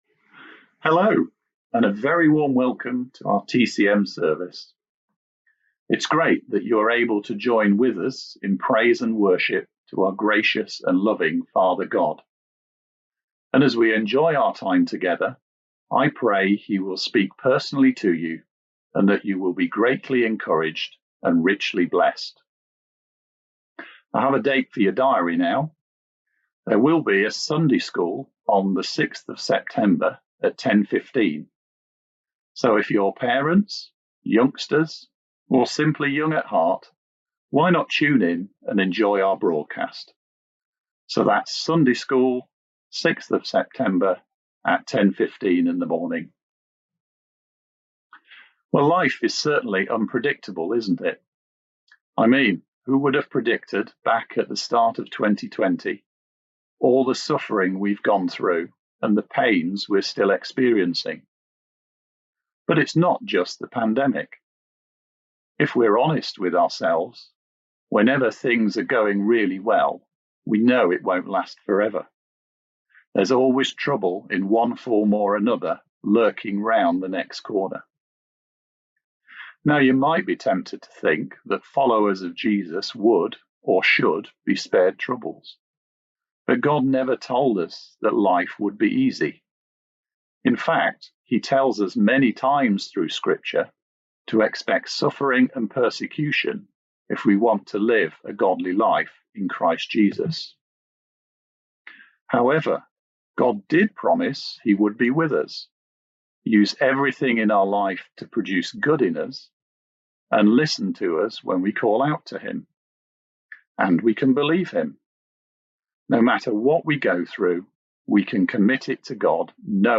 A message from the series "Bridging The Gap."